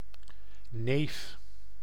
Ääntäminen
IPA : /ˈkʌ.zən/